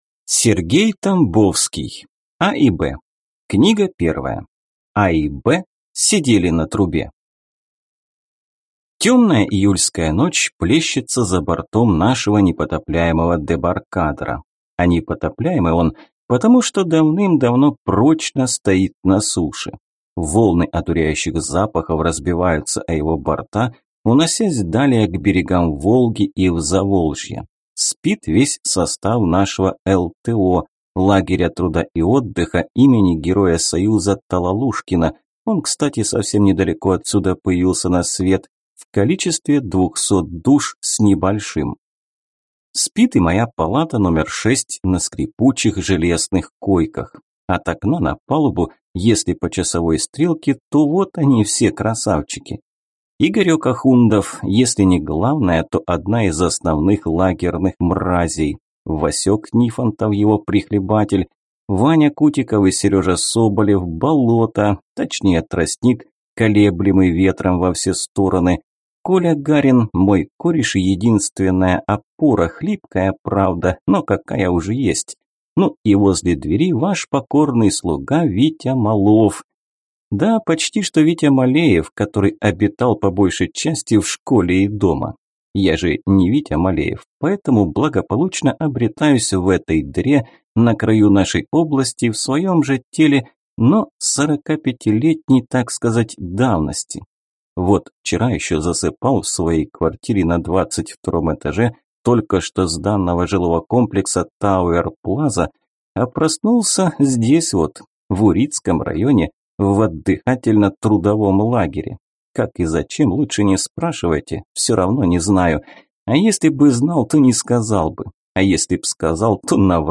Аудиокнига А и Б сидели на трубе. Книга 1 | Библиотека аудиокниг